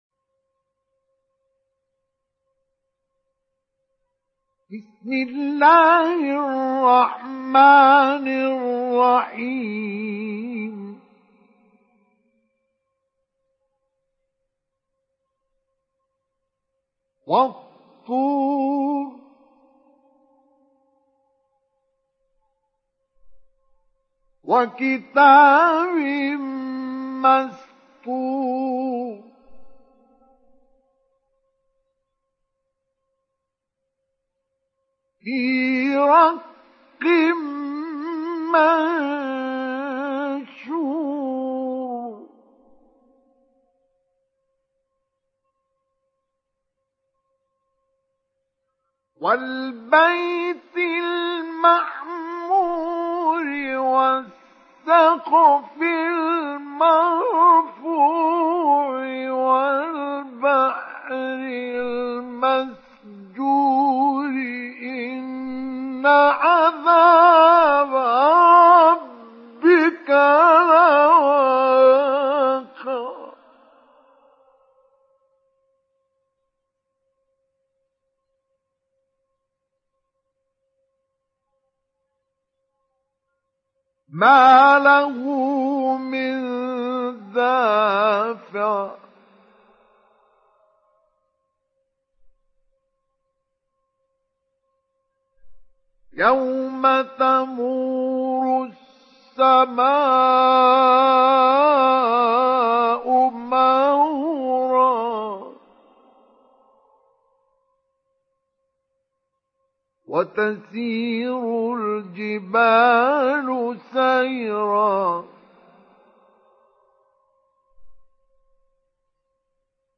Surah At Tur Download mp3 Mustafa Ismail Mujawwad Riwayat Hafs from Asim, Download Quran and listen mp3 full direct links